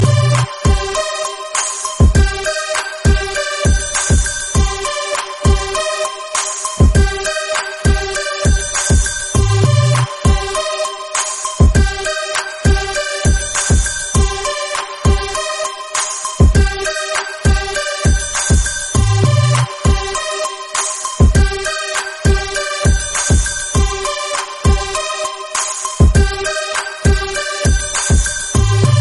электронные Хип-хоп
без слов